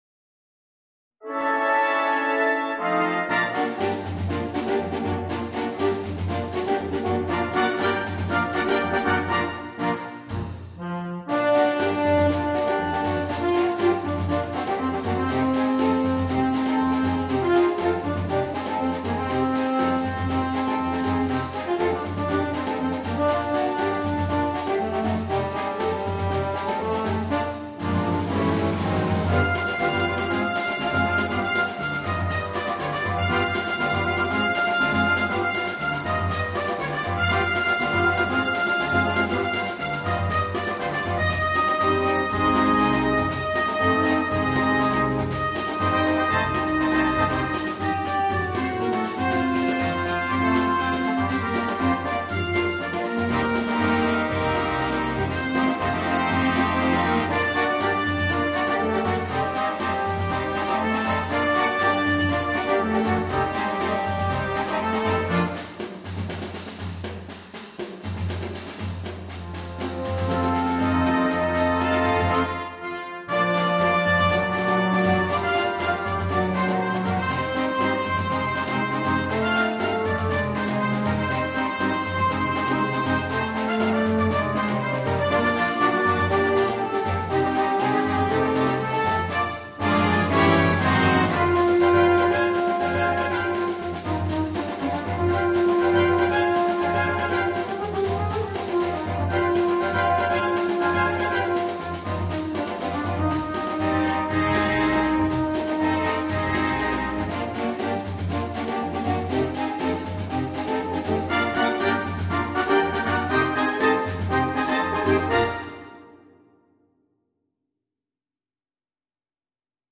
на духовой оркестр